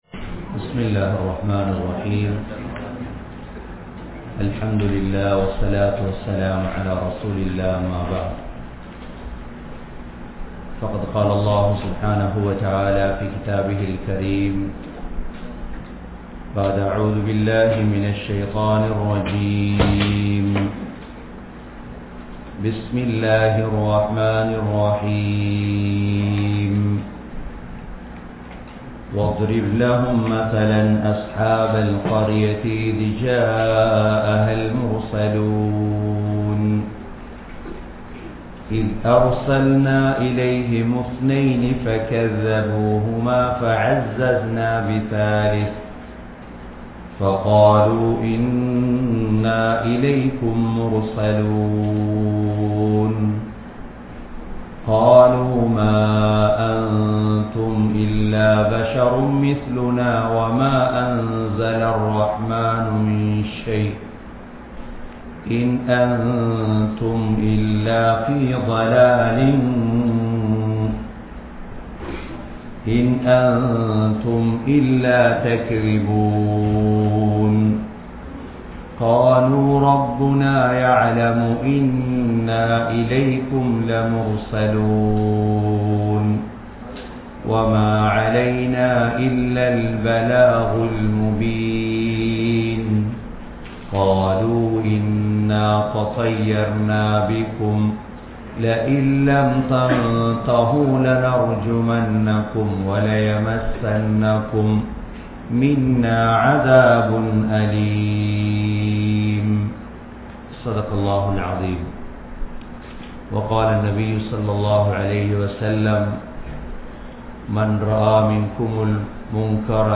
Surah Yaseen (சூரா யாஸீன்) | Audio Bayans | All Ceylon Muslim Youth Community | Addalaichenai
Canada, Toronto, Thaqwa Masjidh